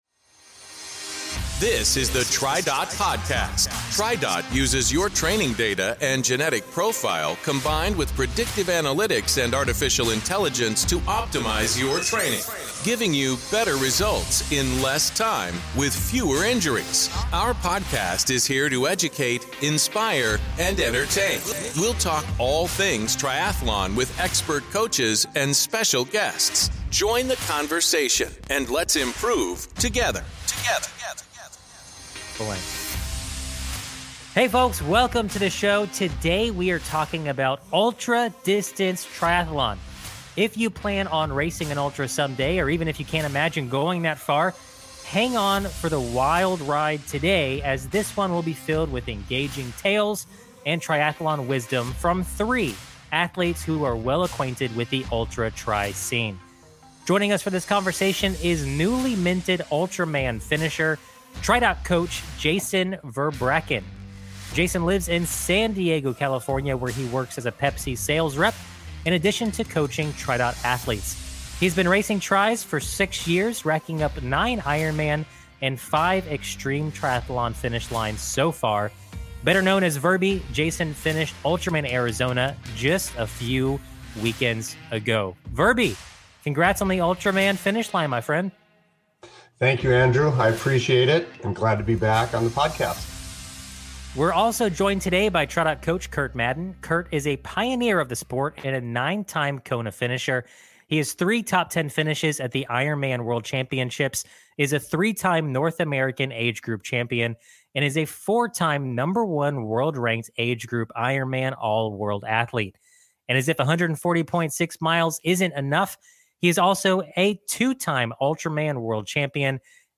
Ultraman Triathlon is a three-day, 515 km (320 mile) multisport race. Today's episode welcomes three ultra-distance competitors